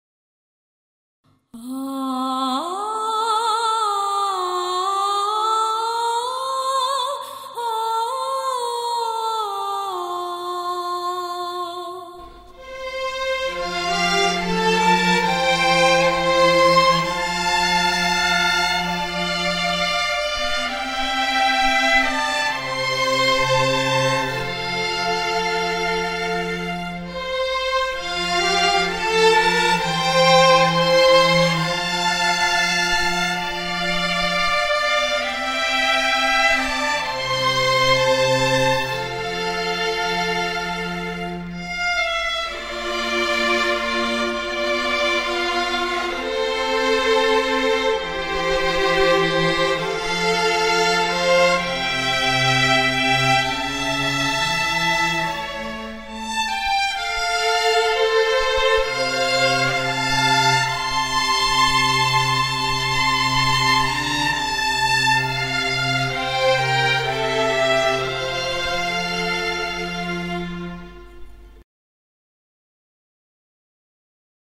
(h�a tấu)